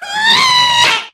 MinecraftConsoles / Minecraft.Client / Windows64Media / Sound / Minecraft / mob / ghast / scream2.ogg
scream2.ogg